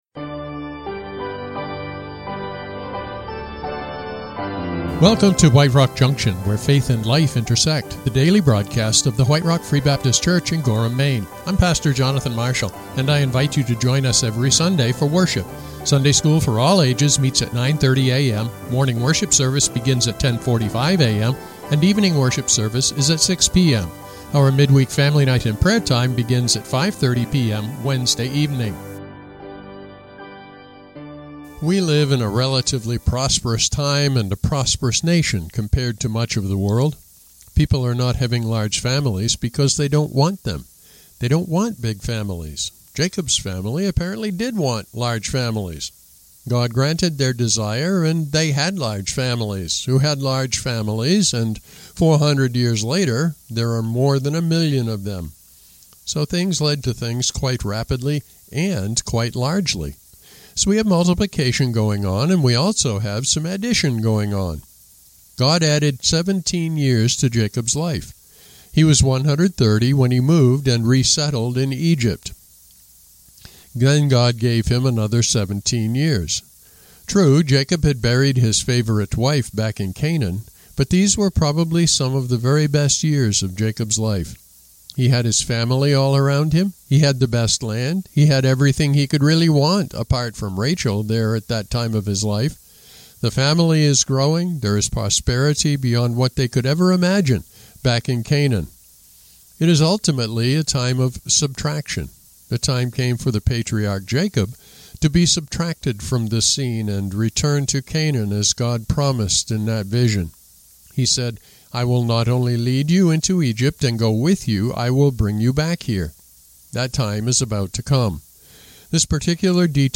The broadcast airs locally on WRKJ 88.5 and WWPC 91.7 FM.